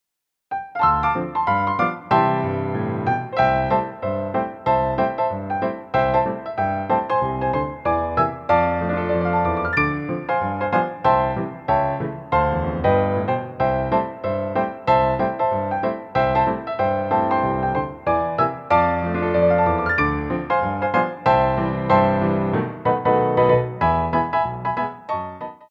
Ronds de Jambé à Terre
3/4 (8x8)